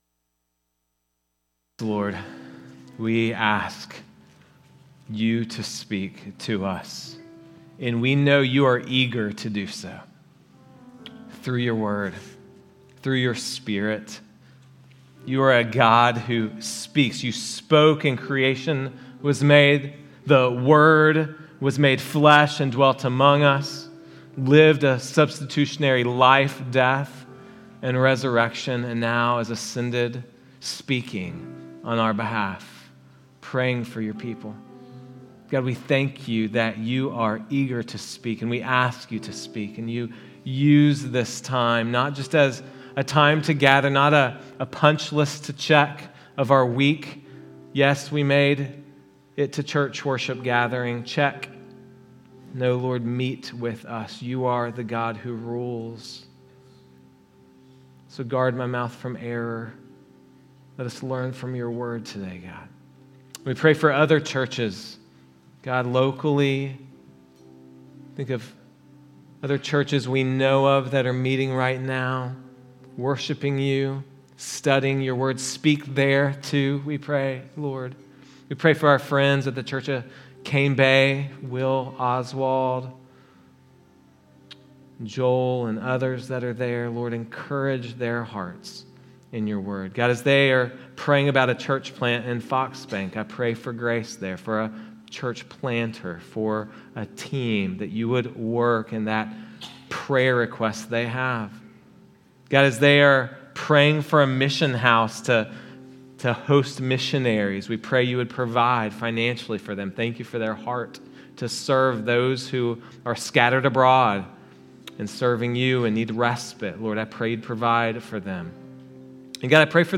Sermons | Risen Hope Church